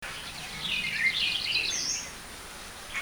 Songs
6 May 2012 Po Toi
Again a poor recording, but all the syllables seem to be similar.